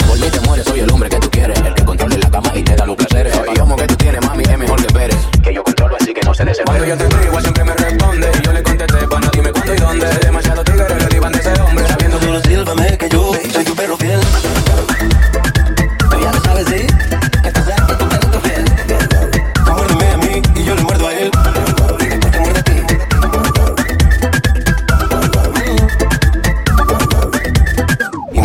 Genere: latin pop, latin urban, reggaeton, remix